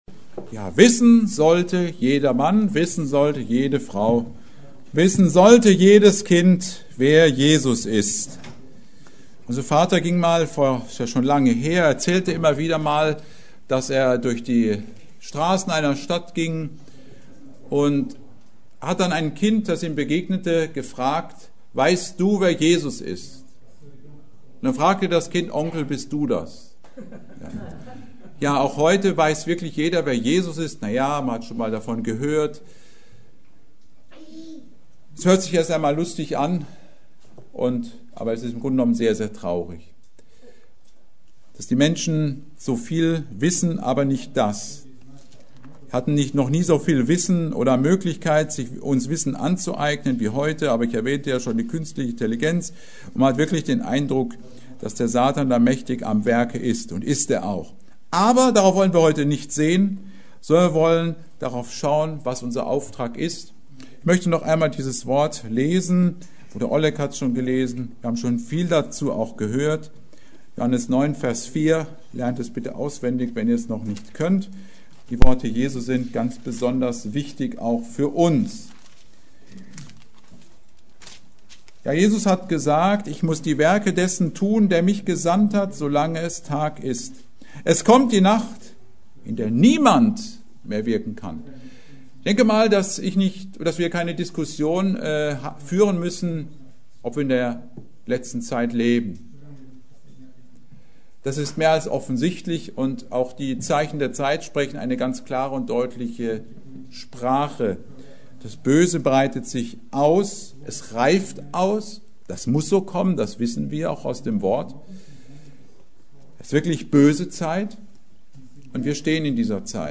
Die Predigt wurde anlässlich des Missionsfestes im Missionshaus in Flehingen gehalten.